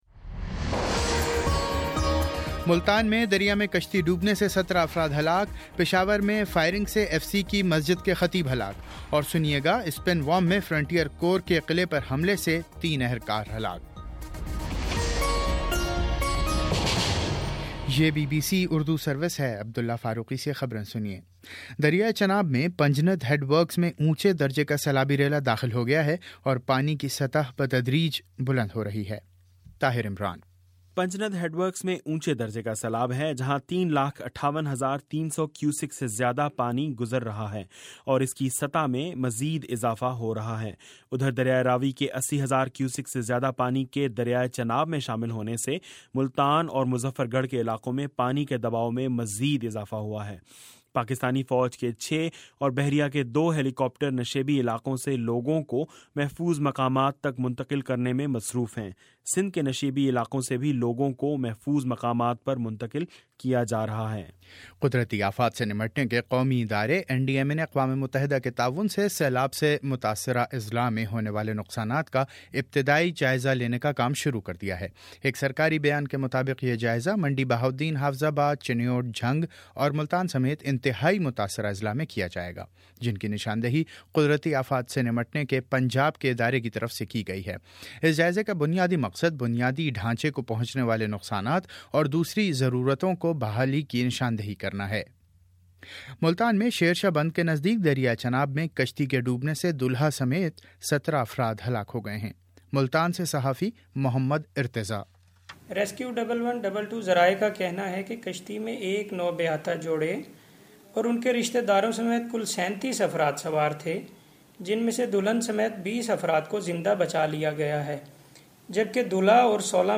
اتوار14 ستمبر : شام چھ بجے کا نیوز بُلیٹن
دس منٹ کا نیوز بُلیٹن روزانہ پاکستانی وقت کے مطابق صبح 9 بجے، شام 6 بجے اور پھر 7 بجے۔